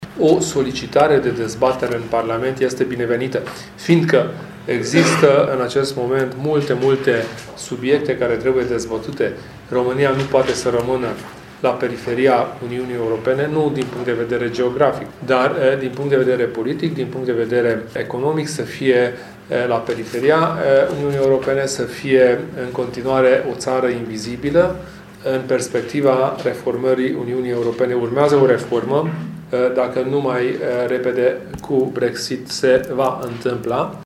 Președintele UDMR, Kelemen Hunor, a declarat azi la Tîrgu-Mureș că partidul pe care îl conduce va studia posibilitatea declanșării unei dezbateri politice pe tema reformării Uniunii Europene: